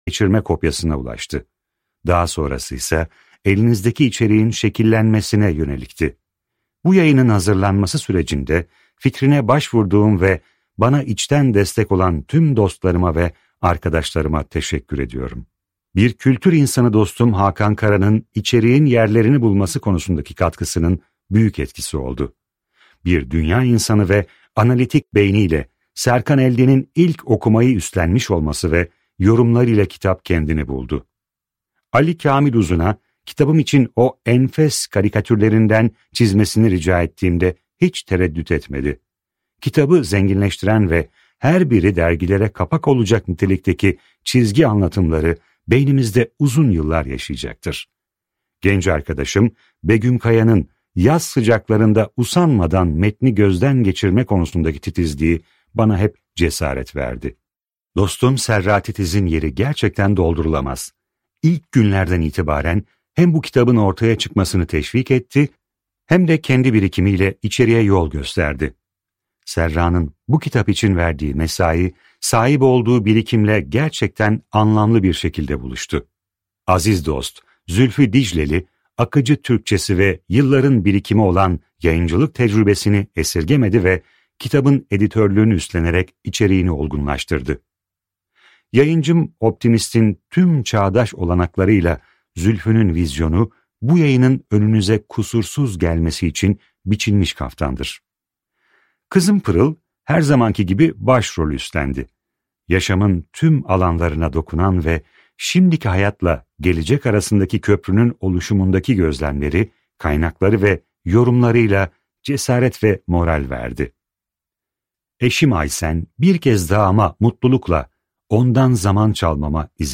Oyun Bitti - Seslenen Kitap